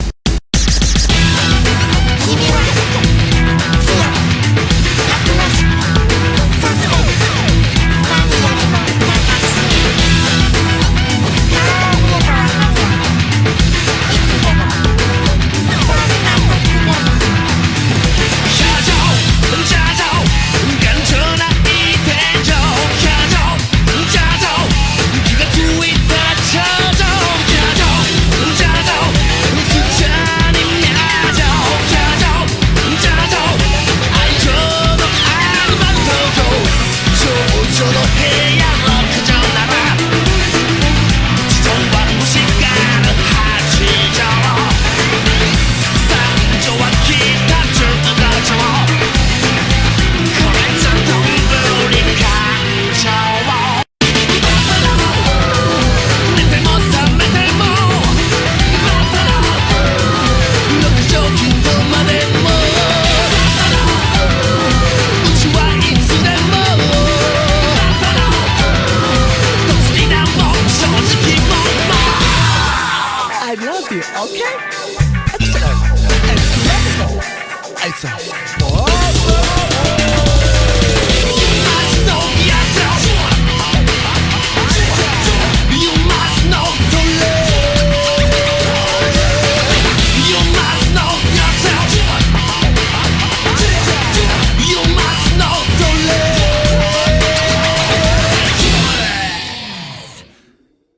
BPM108
Audio QualityMusic Cut